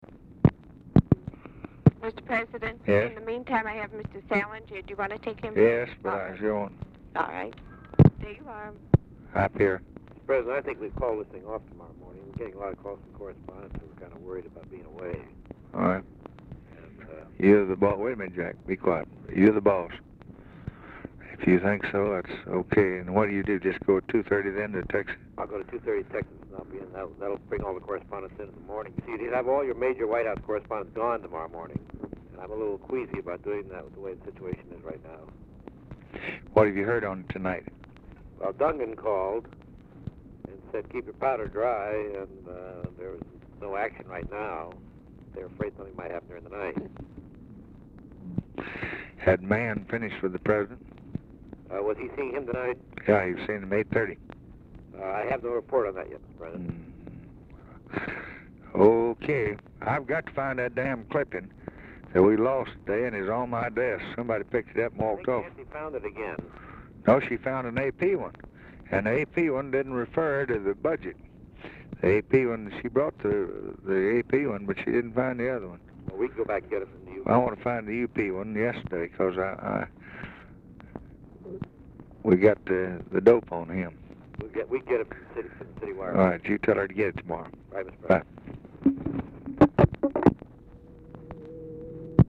Telephone conversation # 1314, sound recording, LBJ and PIERRE SALINGER, 1/10/1964, 10:15PM | Discover LBJ
Format Dictation belt
Location Of Speaker 1 Oval Office or unknown location
Specific Item Type Telephone conversation